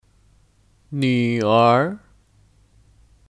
女儿 Nǚ’ér (Kata benda): Anak perempuan